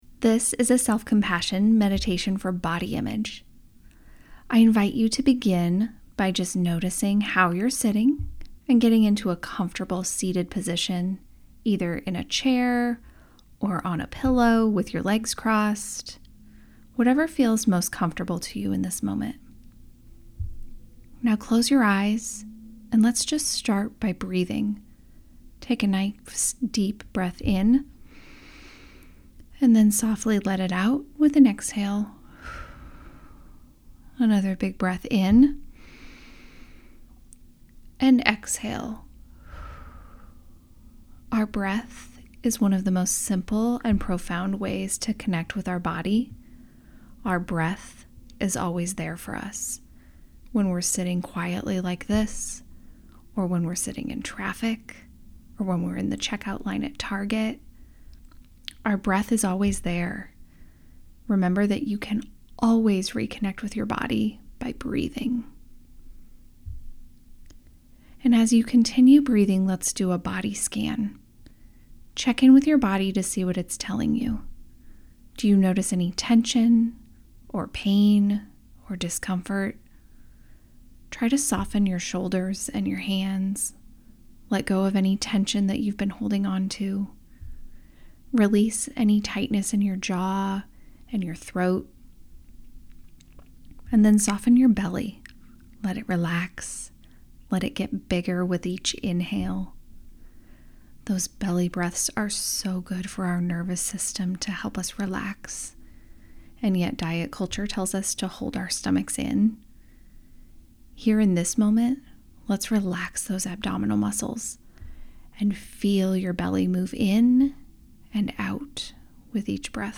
Guided Meditation: Cultivate and practice self-compassion towards your body.